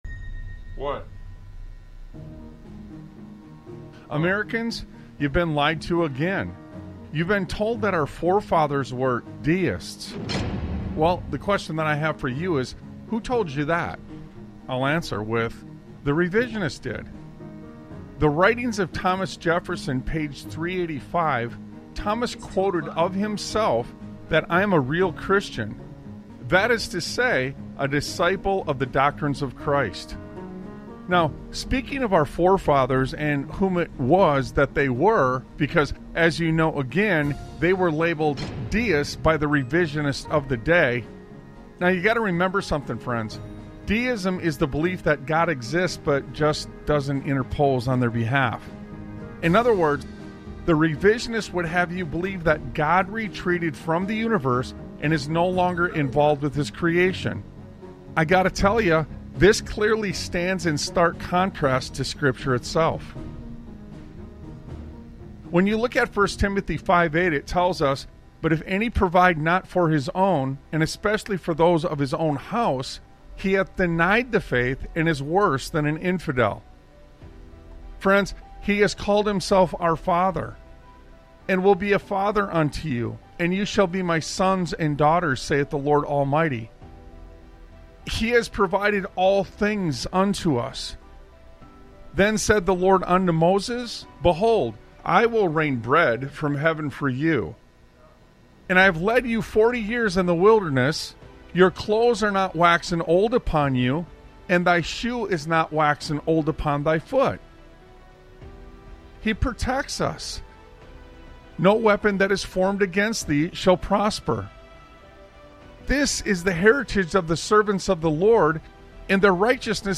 Talk Show Episode, Audio Podcast, Sons of Liberty Radio and Full Circle on , show guests , about Full Circle, categorized as Education,History,Military,News,Politics & Government,Religion,Christianity,Society and Culture,Theory & Conspiracy